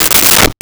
Toilet Seat Fall 02
Toilet Seat Fall 02.wav